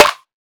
SNARE.66.NEPT.wav